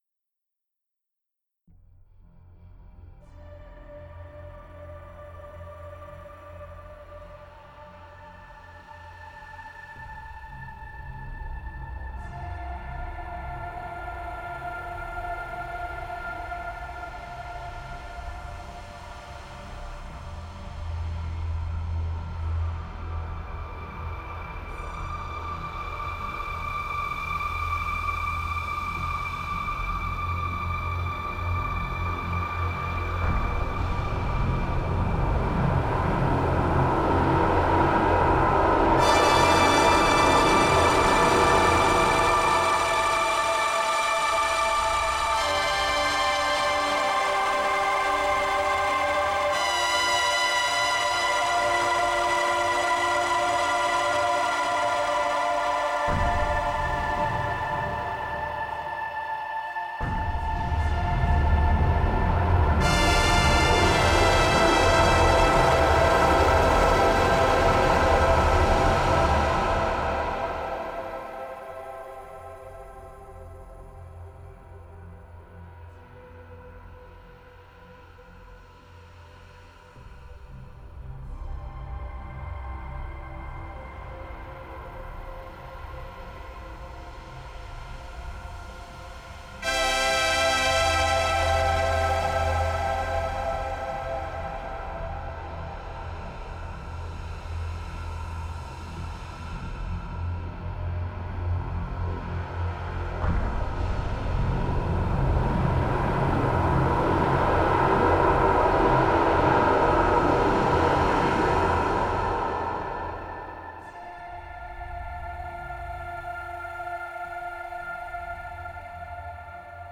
Einmal mit verschiedenen Instrumenten, die in der Software entalten sind und einmal ein Beispiel für die Velocity Dynamik, die bei Minimal und Maximal Anschlag des Keyboards möglich ist.